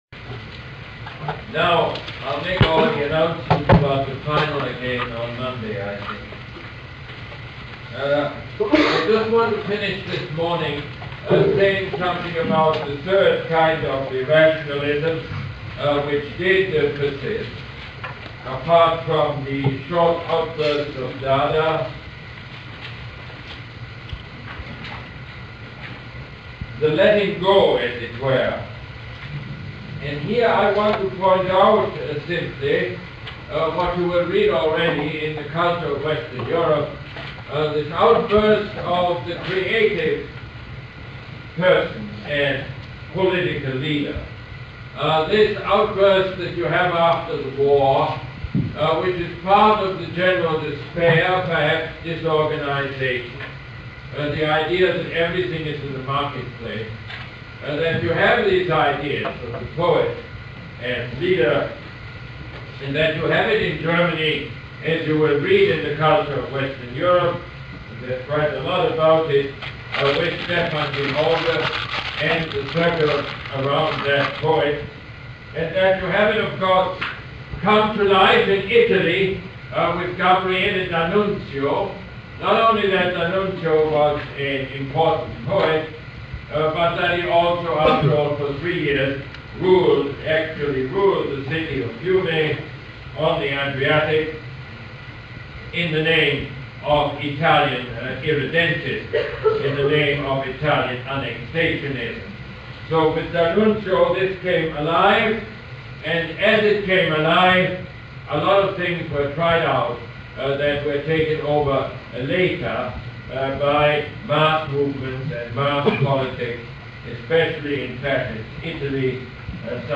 Lecture #25 - November 30, 1979